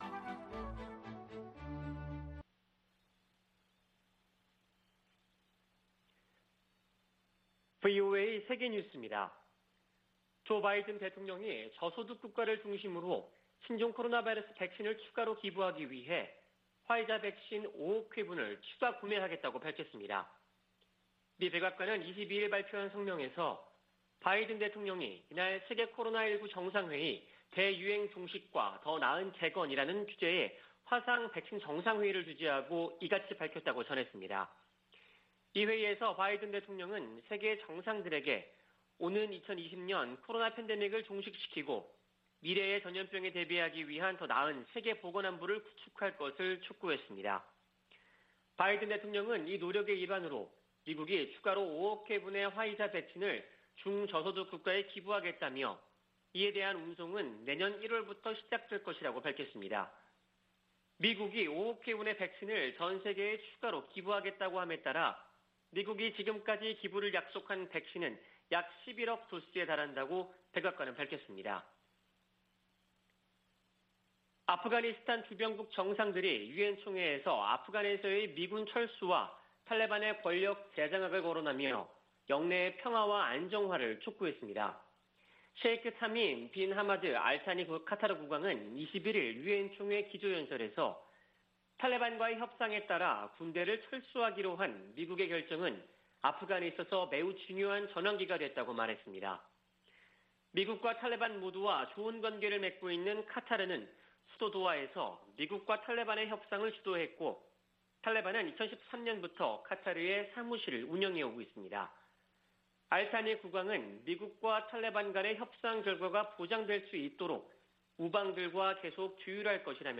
VOA 한국어 아침 뉴스 프로그램 '워싱턴 뉴스 광장' 2021년 9월 23일 방송입니다. 조 바이든 미국 대통령이 한반도 완전 비핵화를 위해 지속적 외교와 구체적 진전을 추구한다고 밝혔습니다. 문재인 한국 대통령은 종전선언을 제안했습니다. 미 연방수사국(FBI)이 북한의 사이버 역량 증대를 지적했습니다.